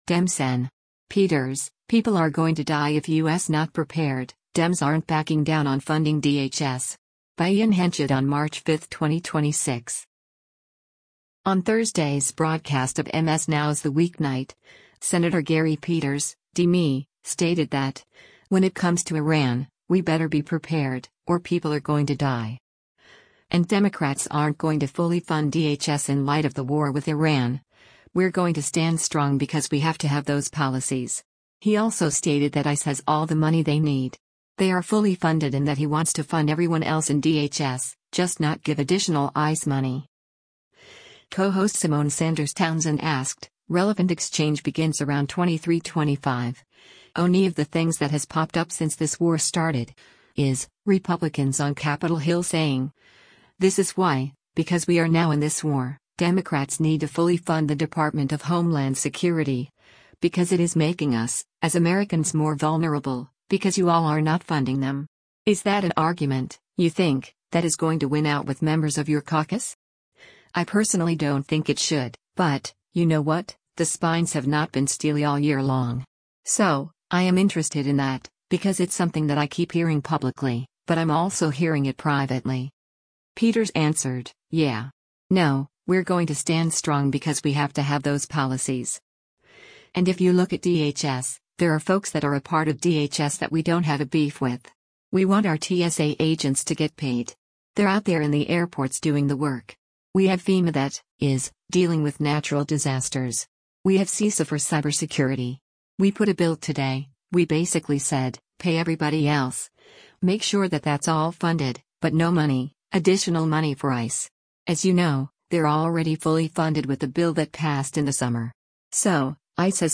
On Thursday’s broadcast of MS NOW’s “The Weeknight,” Sen. Gary Peters (D-MI) stated that, when it comes to Iran, “we better be prepared, or people are going to die.”